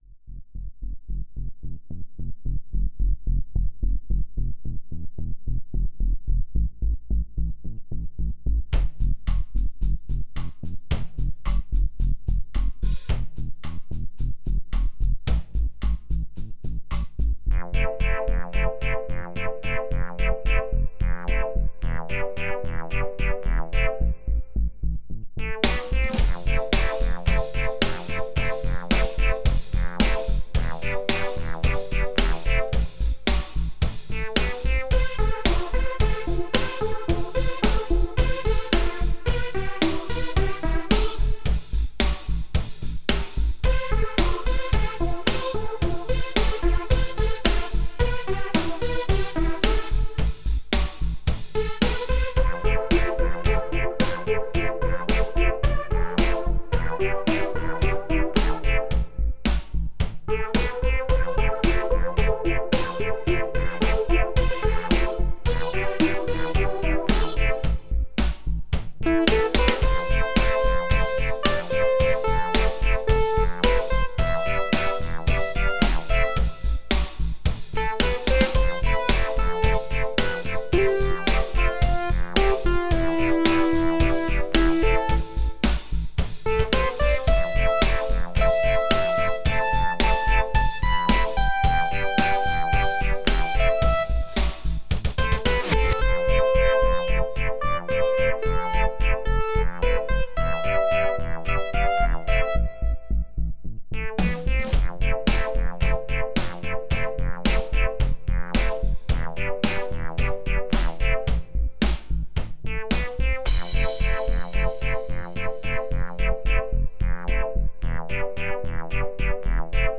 I've been sitting a couple of nights trying to get some lyrics on it, but i didn't end up with anything i liked. The song has quite a bit too synthy feeling in places for my taste.